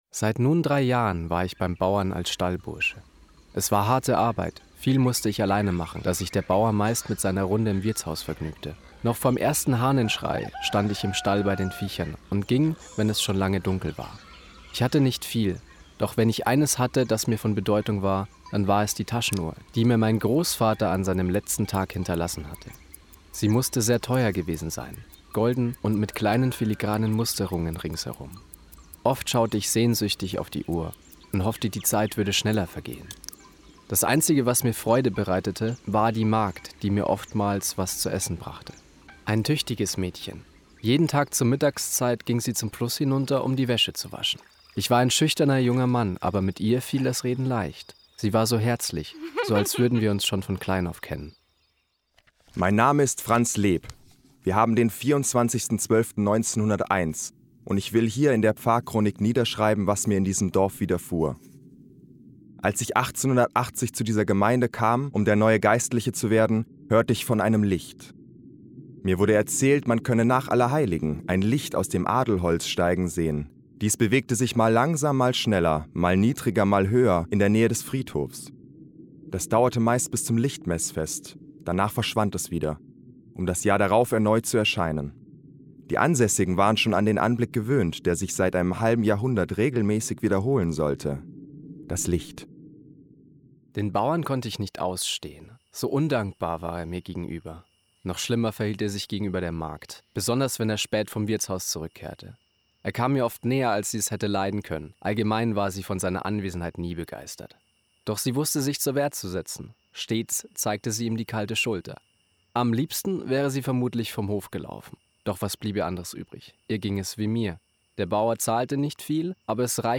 31. Hörspiel
Hoerspiel_DasLicht.mp3